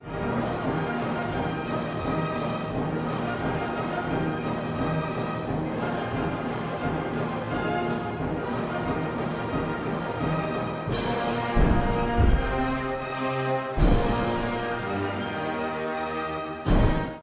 古い音源なので聴きづらいかもしれません！（以下同様）
冒頭はロシア正教の聖歌「神よ、汝の民を救いたまえ」が、ビオラとチェロの六重奏で静かに奏でられます。
聖歌のあと、音楽は緊張感を高め、戦いの幕開けを予感させる展開へとつながっていきます。